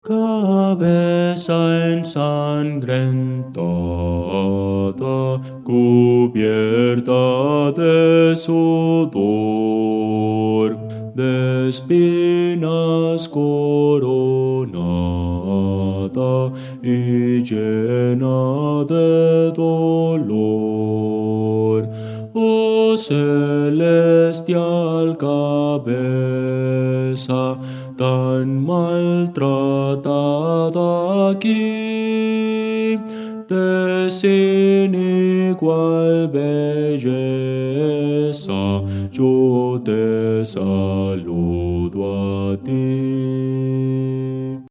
Voces para coro
Bajo
Audio: MIDI